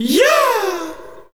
Track 14 - Vocal Yeah OS.wav